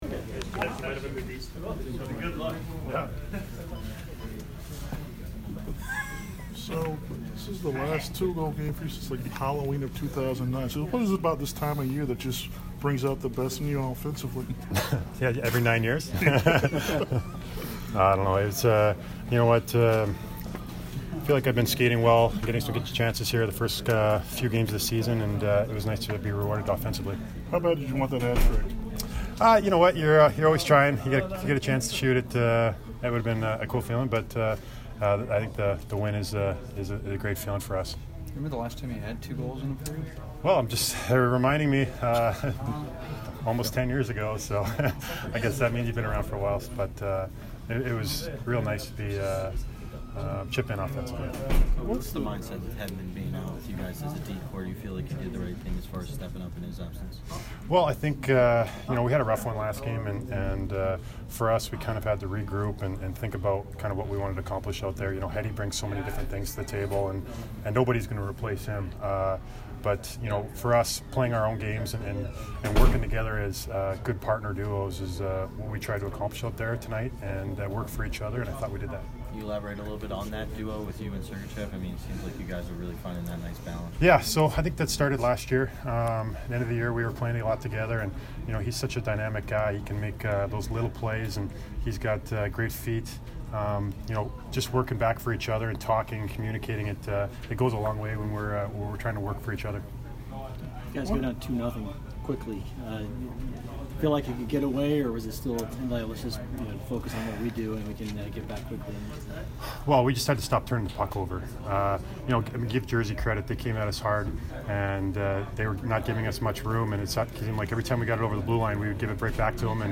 Braydon Coburn post-game 10/30